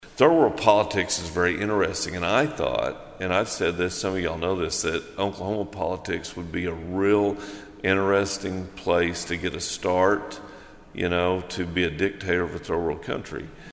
CLICK HERE to listen to commentary from Mark McBride.